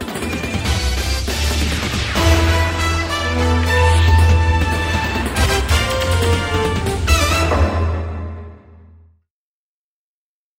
Звуки джинглов
• Качество: высокое
Интро новостей: главные события дня